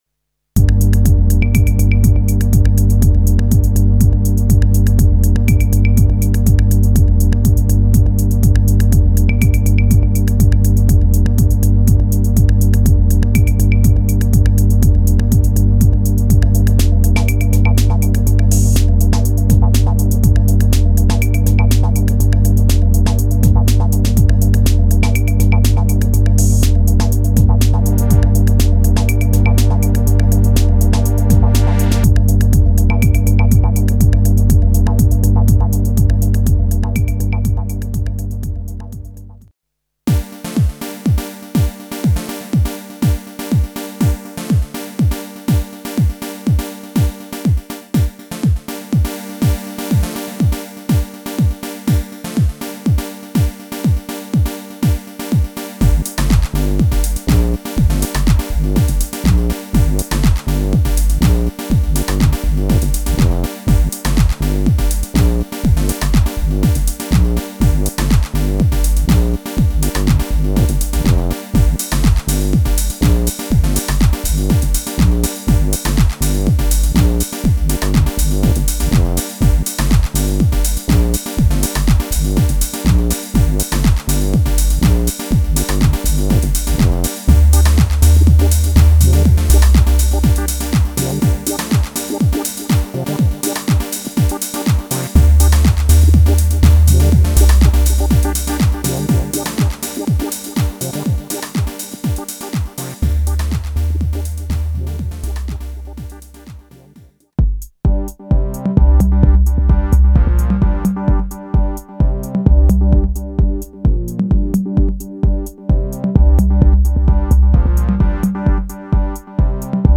Robotic, mechanical and soulful.
All samples except loops are in the key of C.